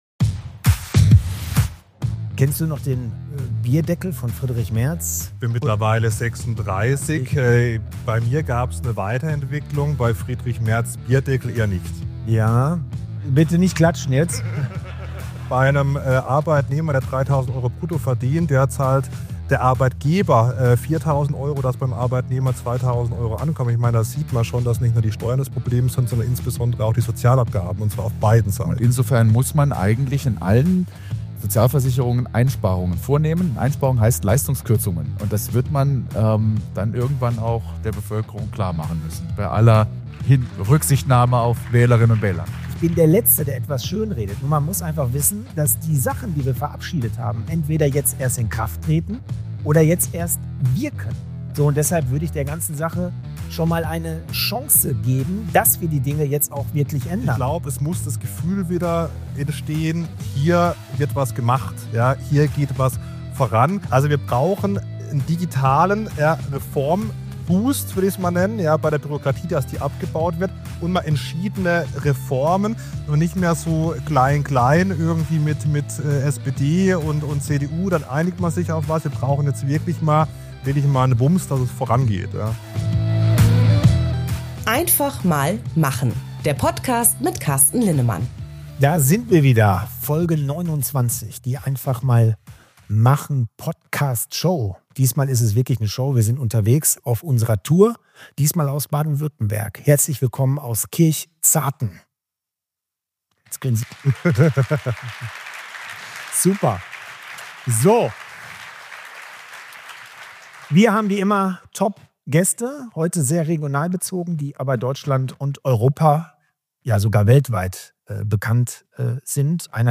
Beschreibung vor 1 Monat Deutschland diskutiert über Steuerentlastung, Bürokratieabbau und Wettbewerbsfähigkeit – doch warum kommt so wenig spürbar an? In dieser Live-Folge der „Einfach mal machen“-Podcast Show aus Kirchzarten sprechen wir mit zwei ausgewiesenen Experten: Prof. Dr. Lars Feld, einer der einflussreichsten Ökonomen Deutschlands und ehemaliger Vorsitzender des Sachverständigenrates, analysiert die strukturellen Schwächen des Standorts Deutschland – von Sozialabgaben über Investitionsbedingungen bis hin zur Reformfähigkeit der Politik.